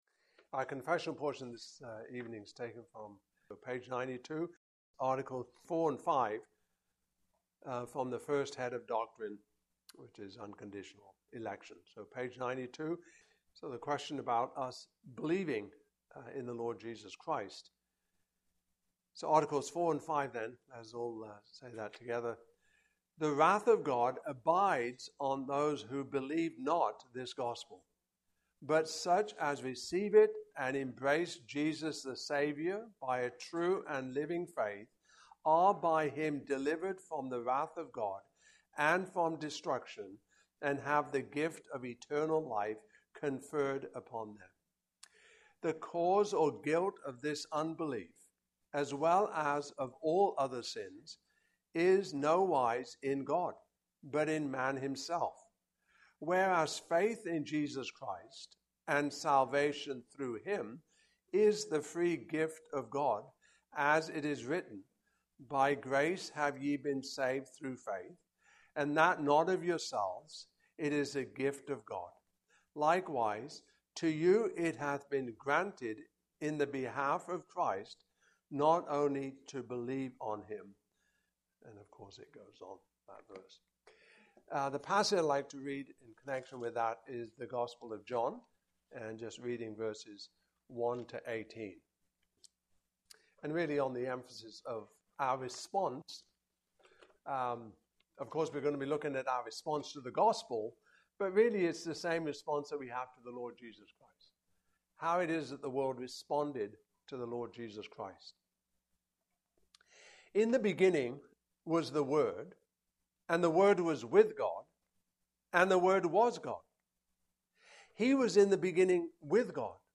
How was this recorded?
Canons of Dordt 2023 Passage: John 1:1-18 Service Type: Evening Service Topics